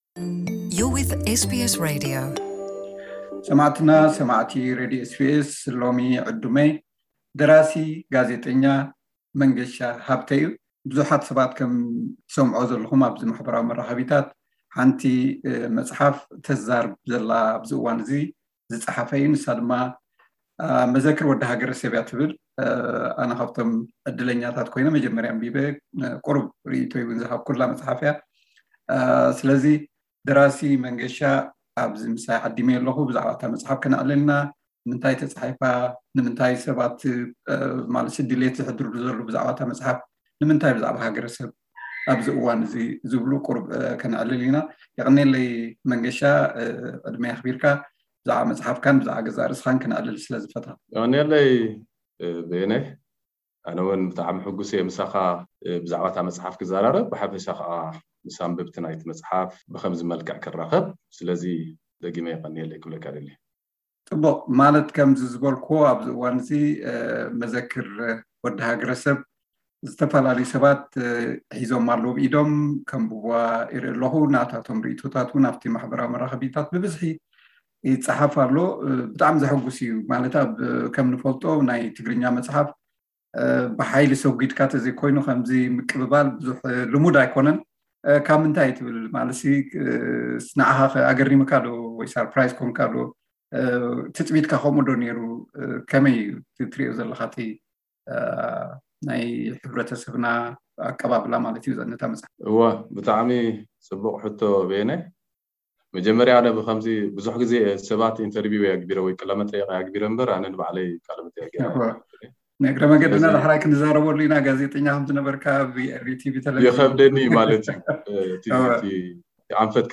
ዝርርብ